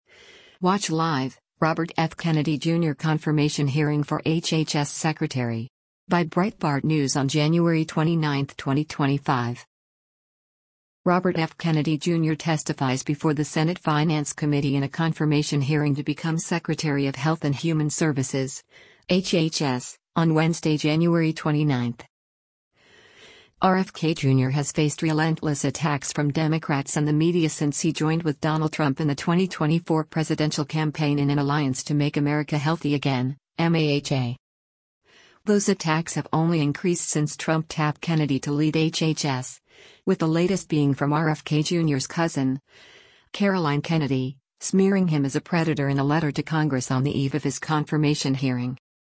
Robert F. Kennedy Jr. testifies before the Senate Finance Committee in a confirmation hearing to become Secretary of Health and Human Services (HHS) on Wednesday, January 29.